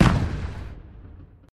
firework_distance_02.ogg